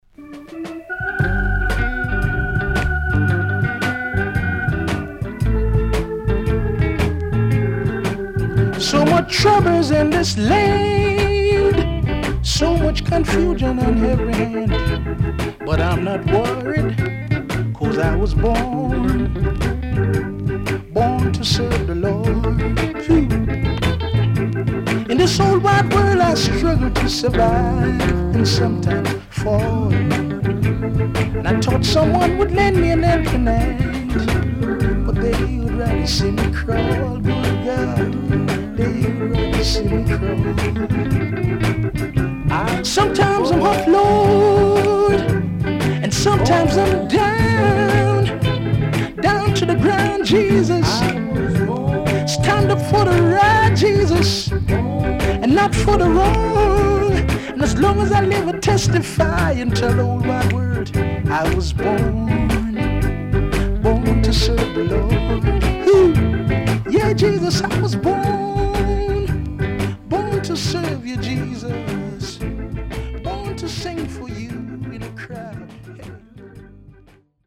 SIDE A:少しチリノイズ、プチノイズ入ります。